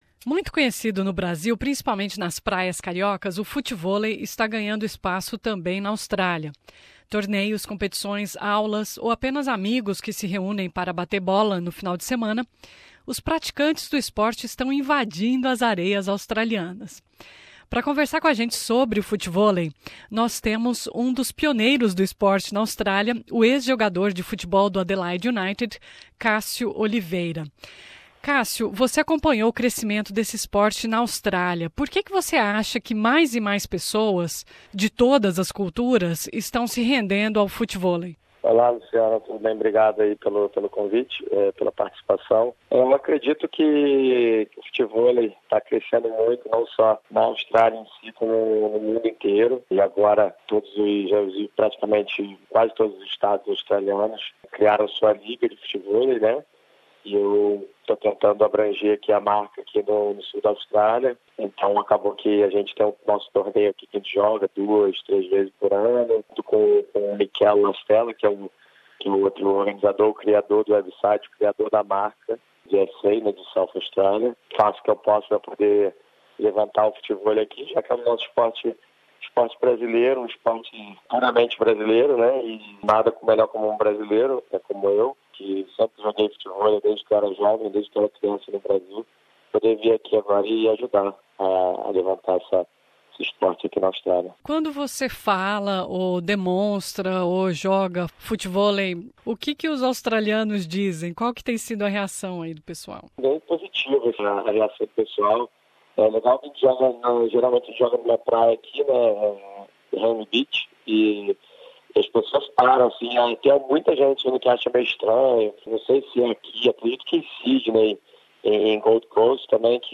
Em entrevista a SBS Português